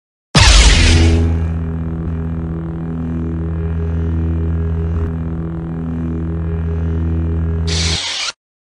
На этой странице собраны реалистичные звуки светового меча из вселенной Star Wars.
Звук оружия света